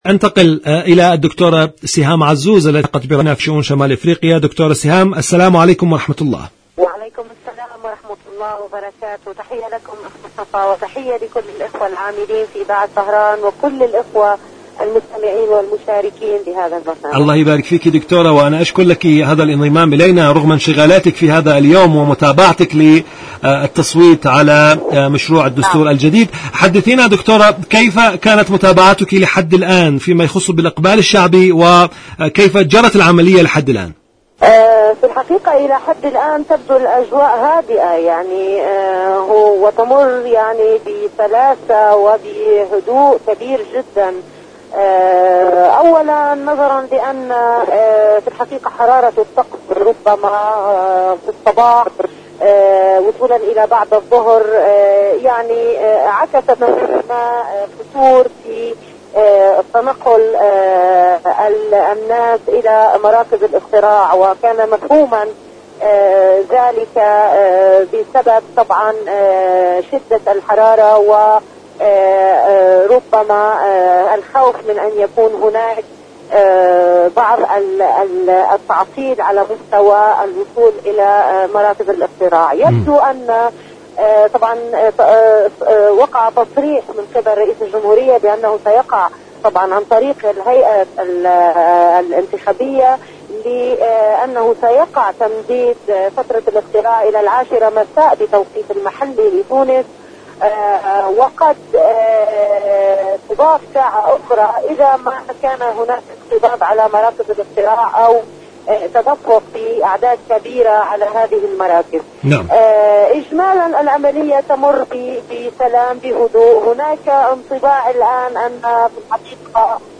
برنامج صدى المغرب العربي مقابلات إذاعية